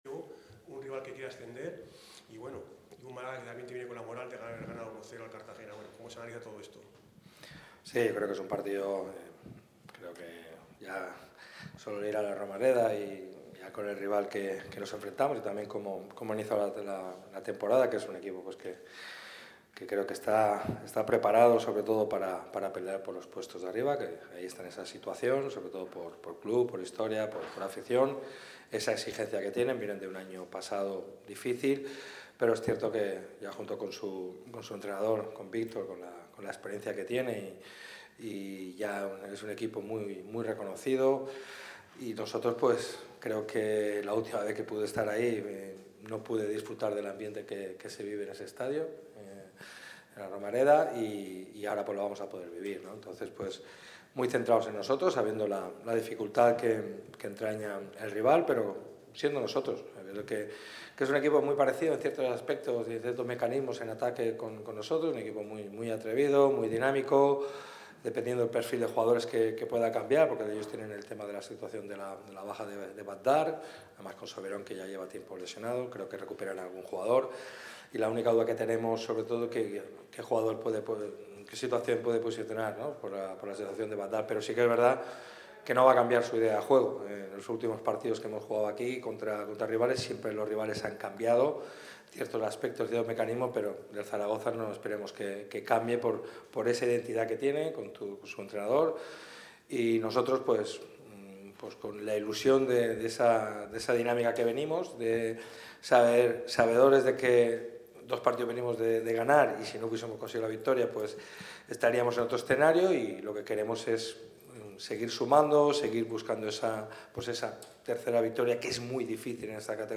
El preparador malaguista ha comparecido ante los medios con motivo de la previa del encuentro que enfrentará a los boquerones contra el Real Zaragoza este domingo a las 21:00 horas. El entrenador del Málaga CF analiza al cuadro rival, que es el tercer clasificado en la tabla, habla sobre el estado del equipo y cita varios nombres propios de la plantilla.